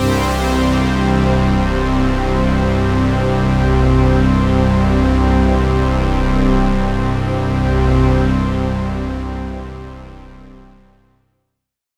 36ac01pad-c.aif